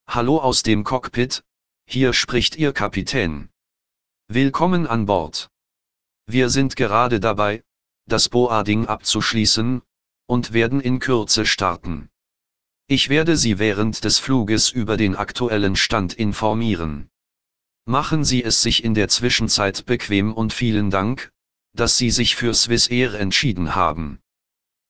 BoardingWelcomePilot.ogg